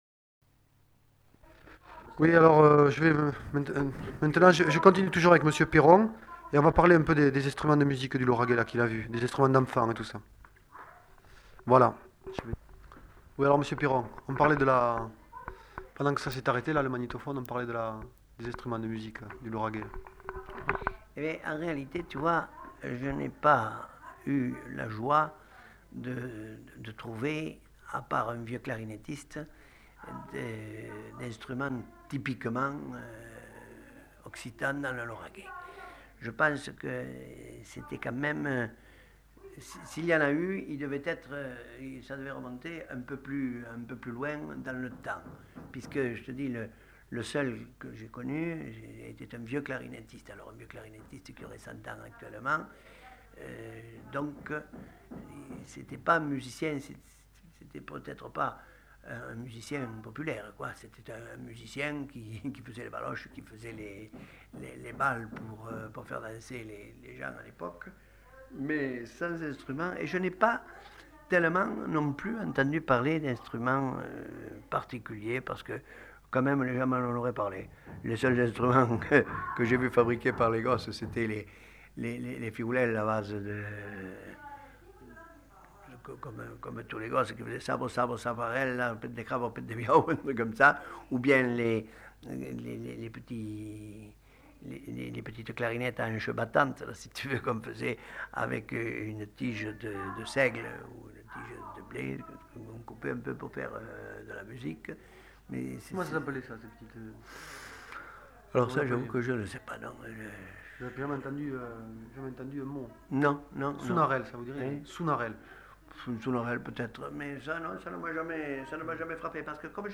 Lieu : Toulouse
Genre : témoignage thématique